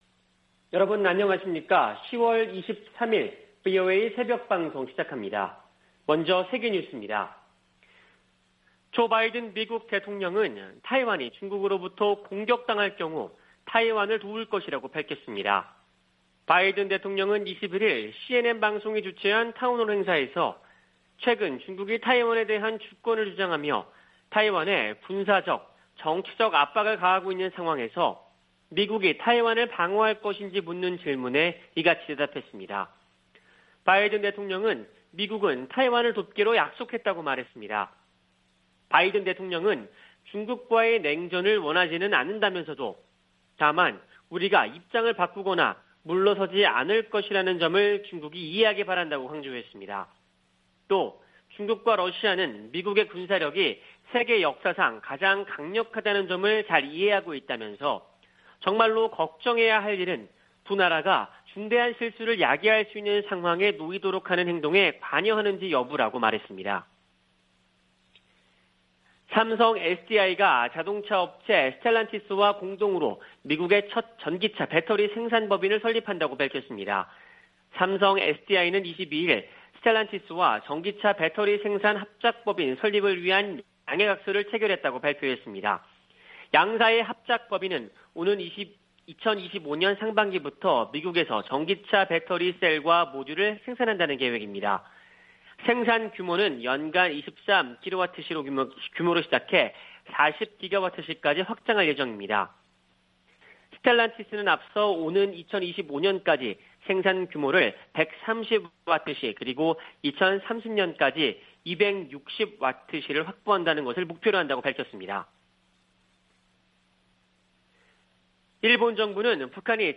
세계 뉴스와 함께 미국의 모든 것을 소개하는 '생방송 여기는 워싱턴입니다', 2021년 10월 23일 아침 방송입니다. '지구촌 오늘'에서는 타이완이 중국의 공격을 받을 경우 방어에 나설 것이라고 조 바이든 미국 대통령이 밝힌 소식, '아메리카 나우'에서는 도널드 트럼프 전 대통령 측근 스티브 배넌 씨 의회 모독죄 고발안 가결 이야기 전해드립니다.